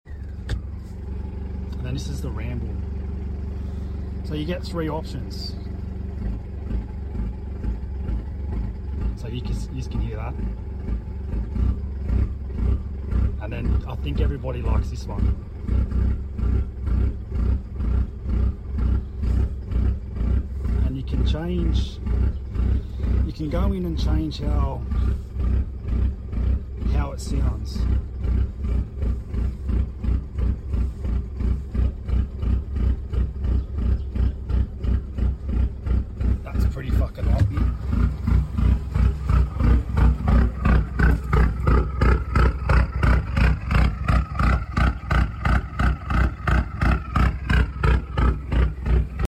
How do i get the lumpy idle.